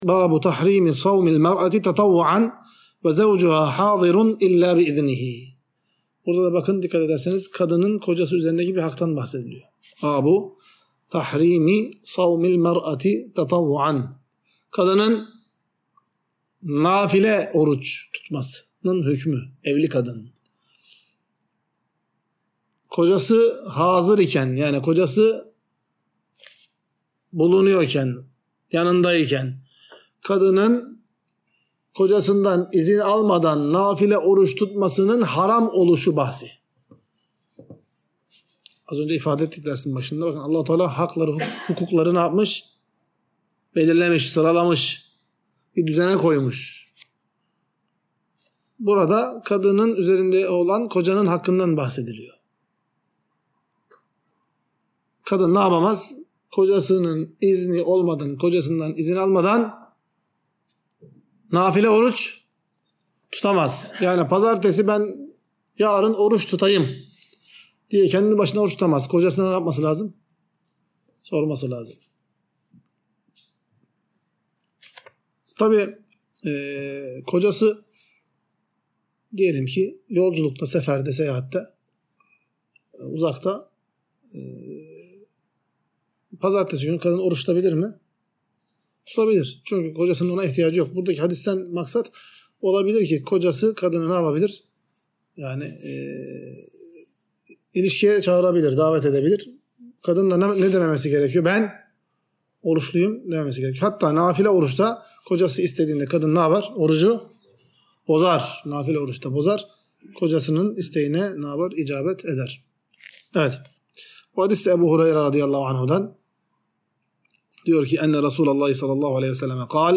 Ders - 83.